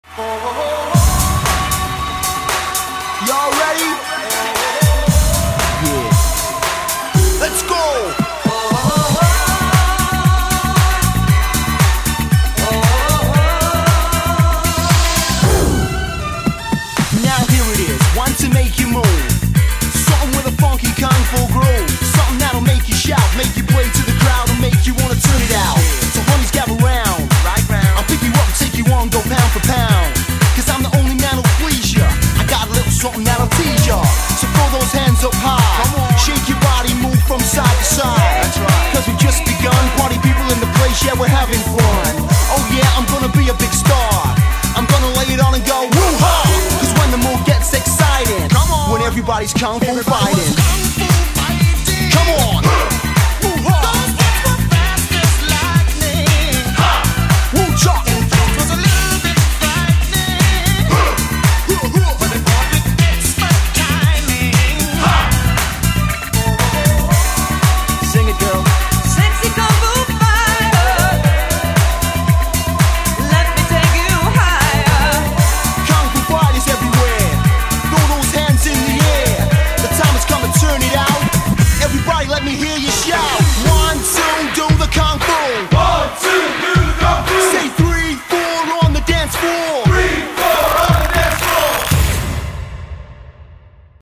BPM116--1
Audio QualityPerfect (High Quality)